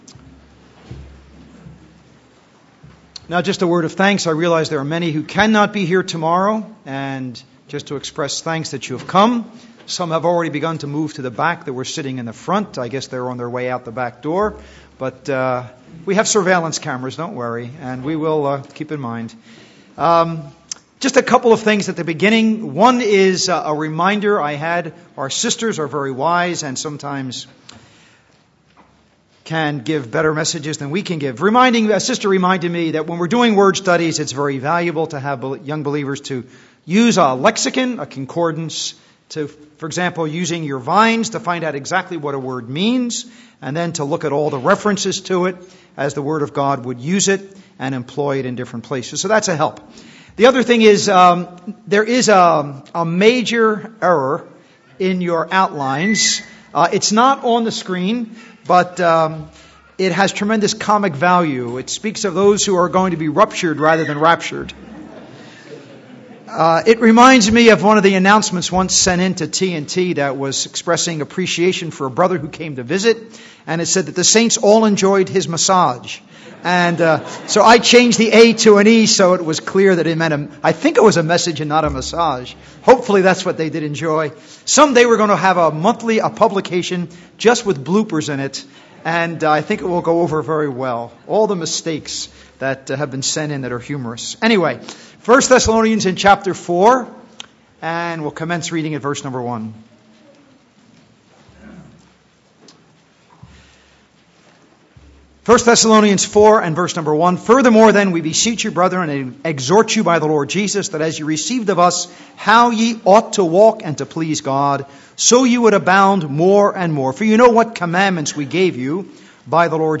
Series: How to Study Your Bible Service Type: Ministry